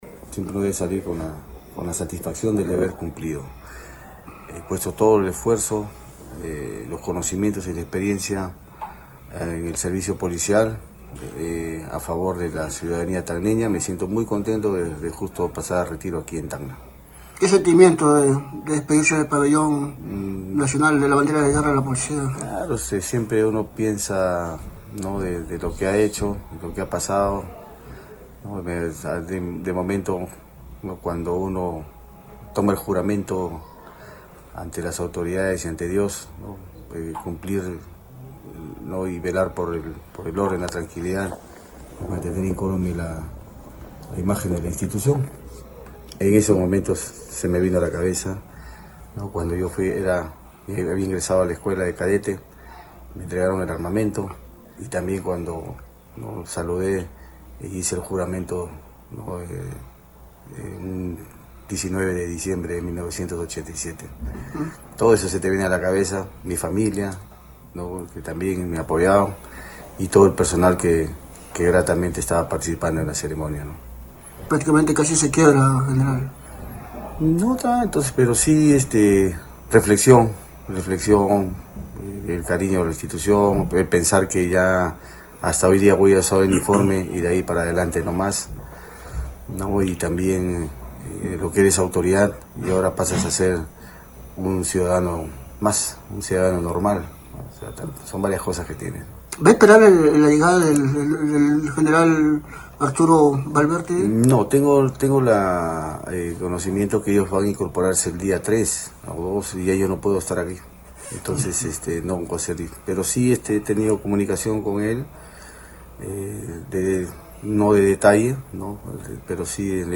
Tras 40 años de servicio a la Policía Nacional del Perú (PNP), el general Edinson Hernández Moreno pasa al retiro. En conversación con Radio Uno, expresó agradecimiento a la región por el tiempo cumplido como máxima autoridad de la PNP.